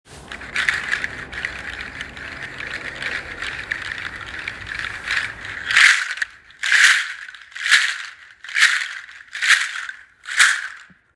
• large seed shaker originally from Togo
• loud hollow clacking sound
60 seed wood handle audio sample
Toga-wood-handle-60-seed.m4a